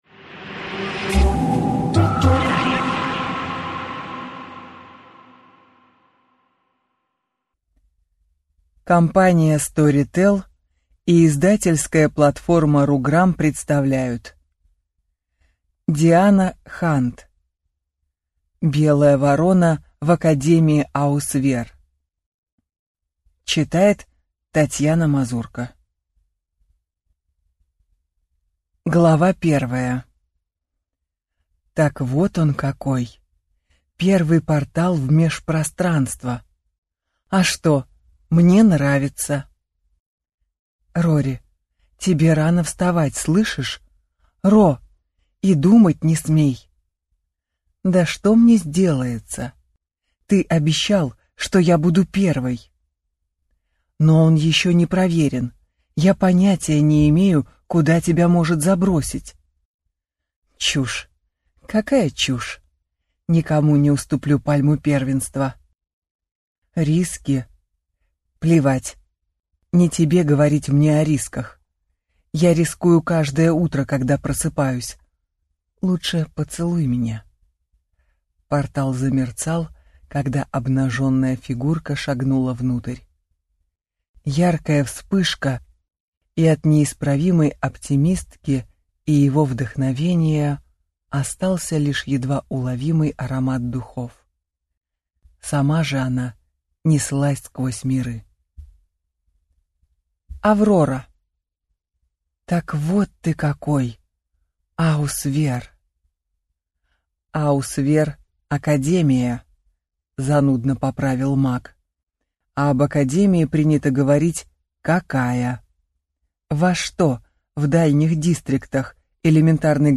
Аудиокнига Белая ворона в Академии Аусвер | Библиотека аудиокниг